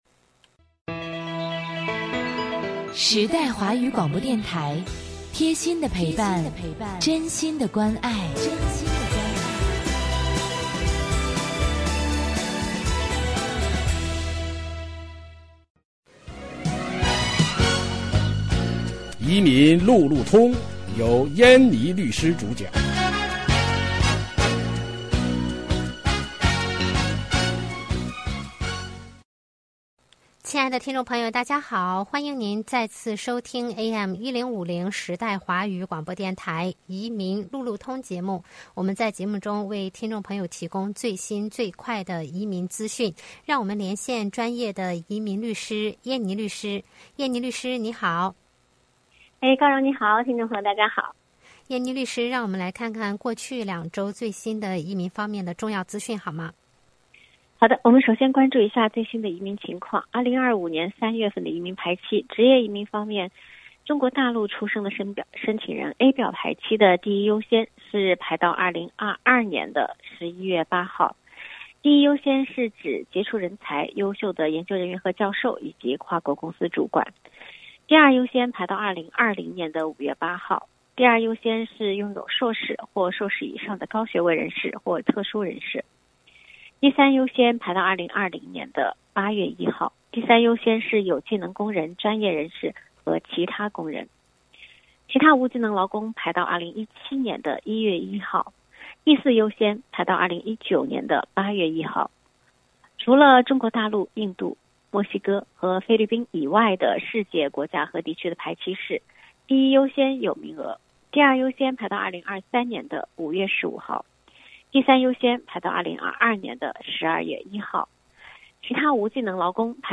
每月第二、第四个周一下午5点30分，AM1050时代华语广播电台现场直播，欢迎听众互动。